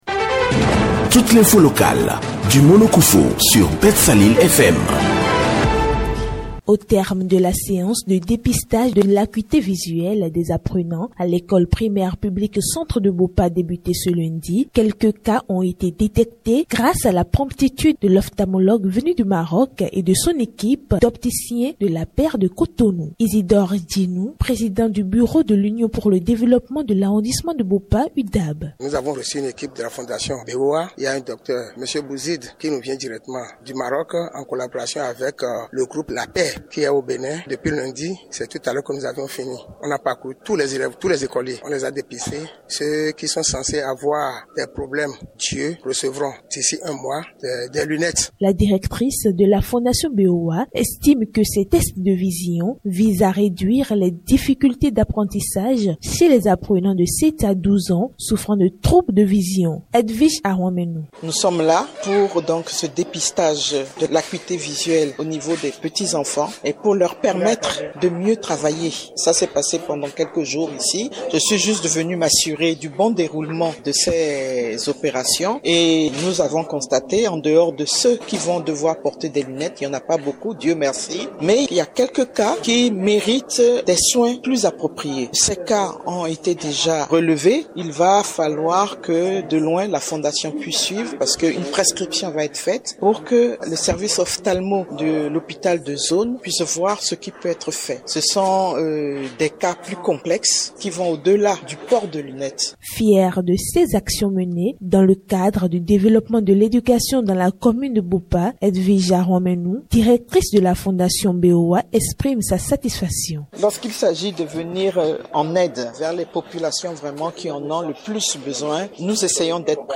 L’objectif poursuivi est d’améliorer la performance scolaire des élèves qui trainent d’éventuels problèmes d’yeux. L’opération s’est déroulée en présence des autorités communales, du personnel enseignant et du bureau de l’association des parents d’élèves. Suivez ce reportage